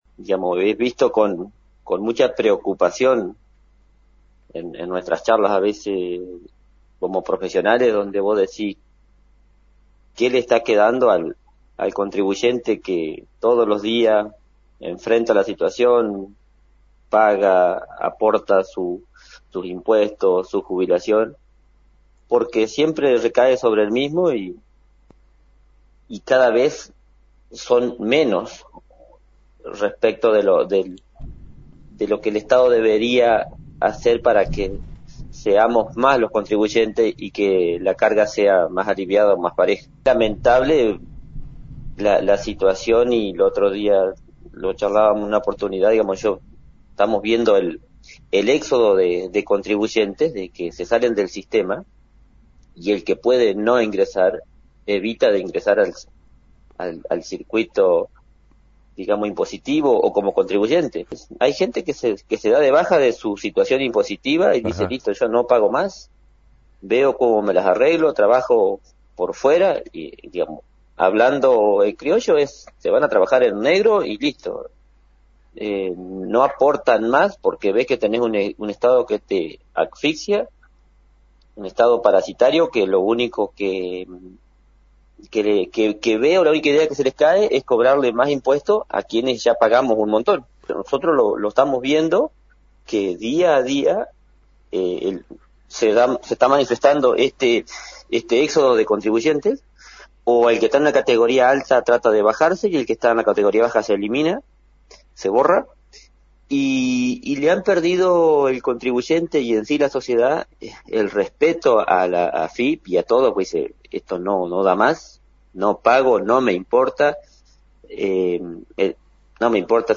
En diálogo
en la mañana sabatina rural de “LA 39”